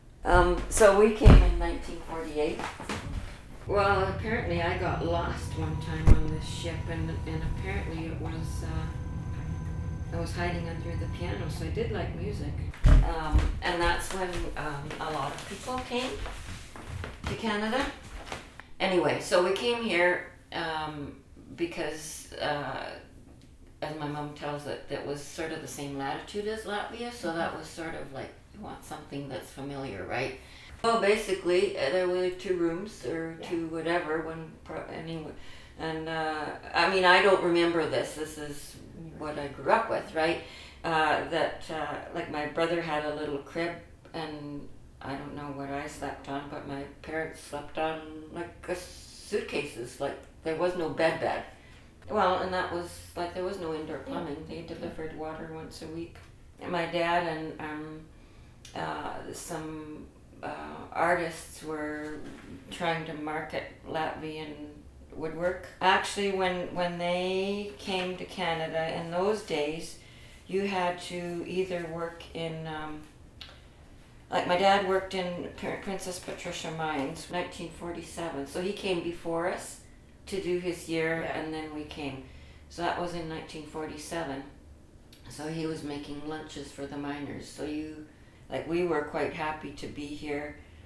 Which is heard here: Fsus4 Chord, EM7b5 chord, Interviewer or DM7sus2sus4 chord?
Interviewer